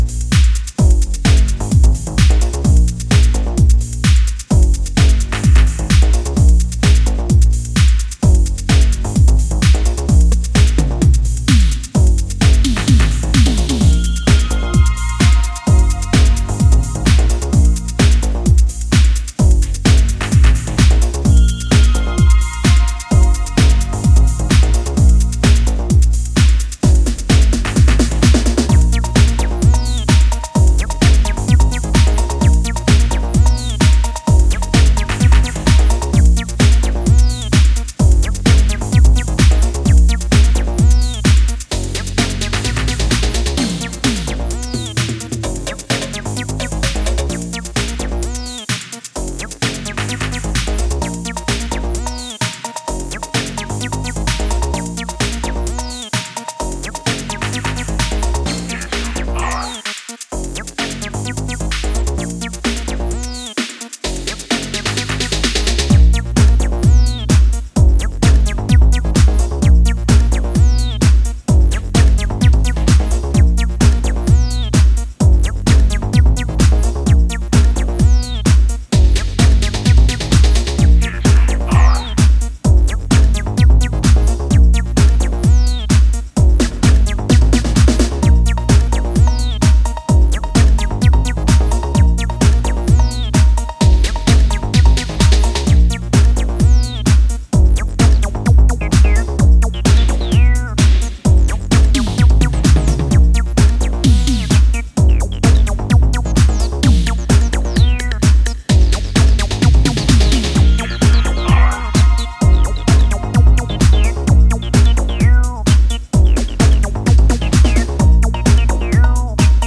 electro boogie